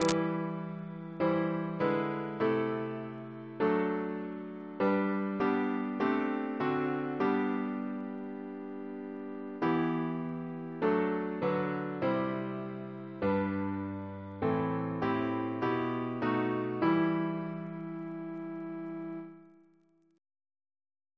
CCP: Chant sampler
Double chant in E minor Composer: William T. Best (1826-1897) Reference psalters: ACP: 33; H1940: 700